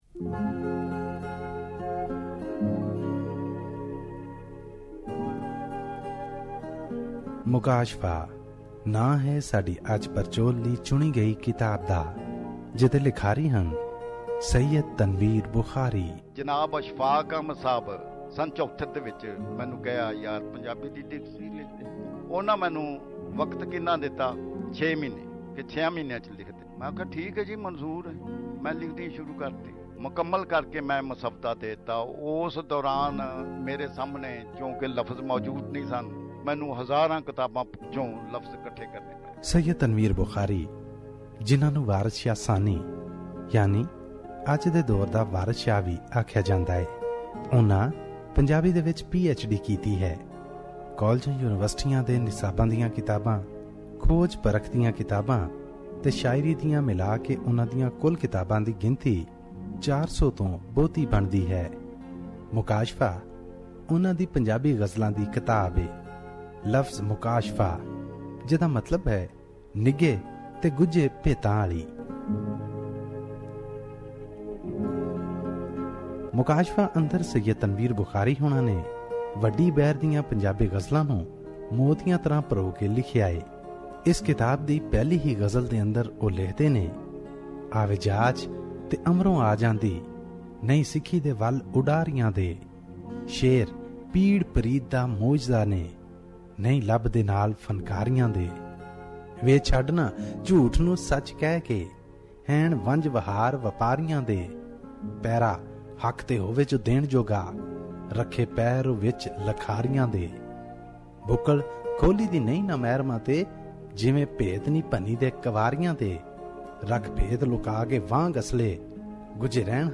Shahmukhi Punjabi Book Review ‘Mukashfa’